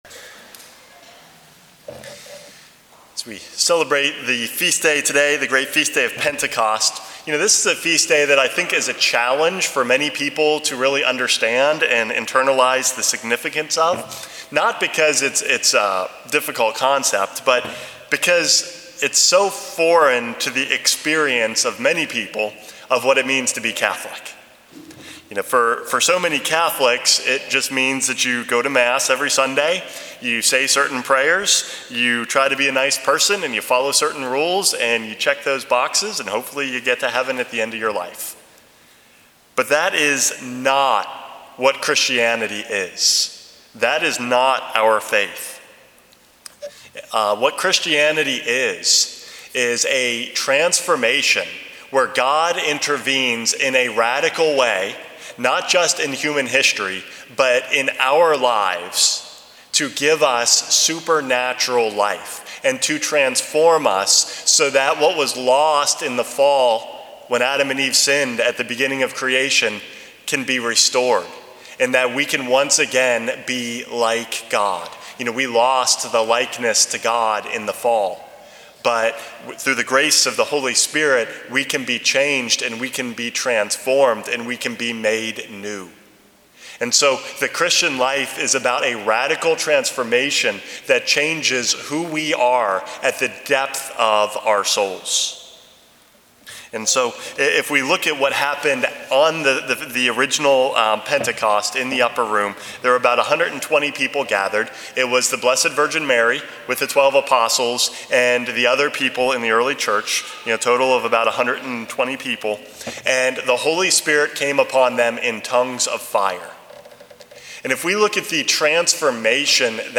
Homily #453 - Pentecost Today